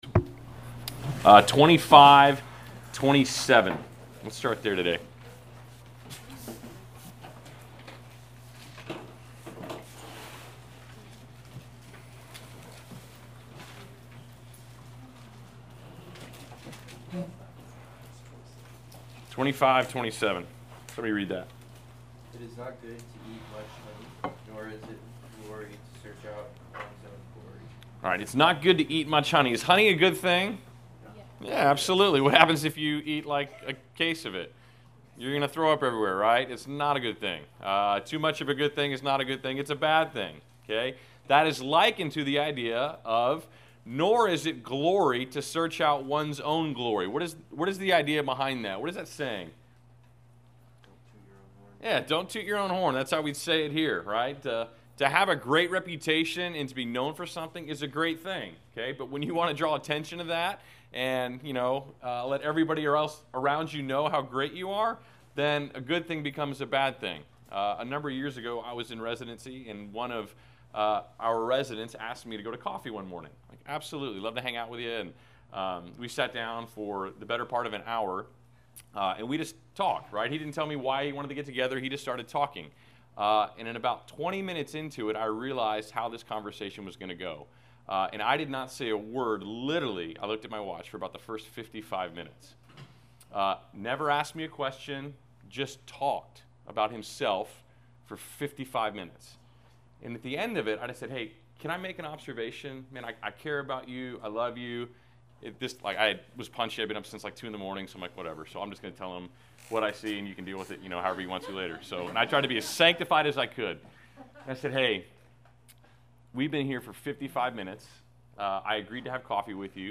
Class Session Audio September 27